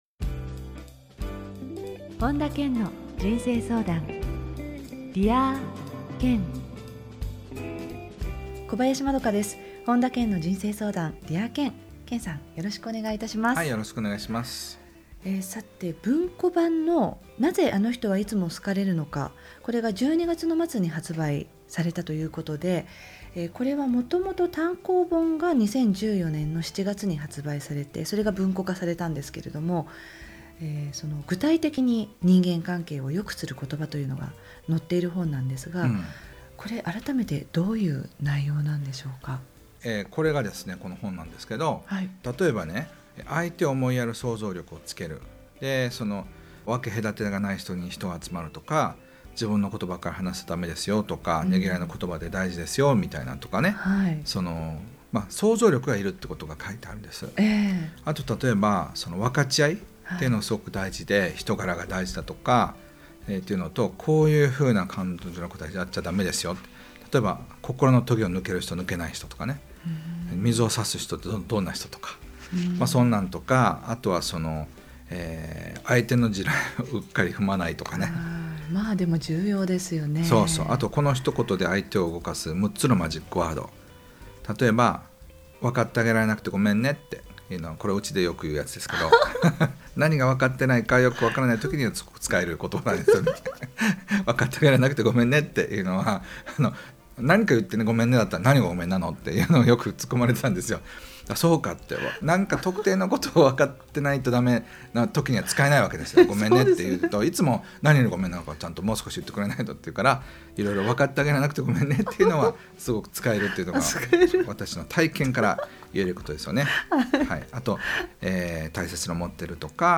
本田健の人生相談 ～Dear Ken～ 傑作選 今回は「誰かと意見が合わない時の対処法」をテーマに、本田健のラジオミニセミナーをお届けします。